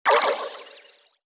Spell_01.wav